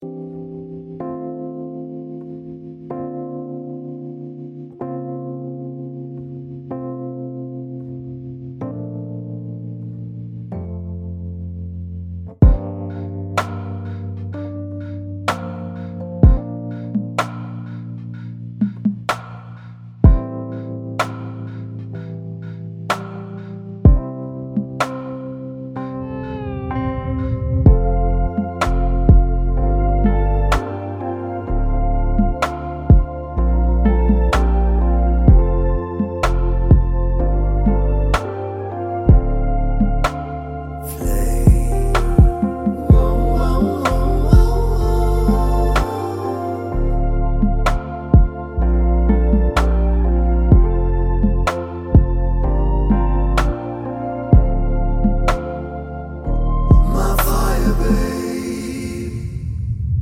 With Female Lead R'n'B / Hip Hop 3:41 Buy £1.50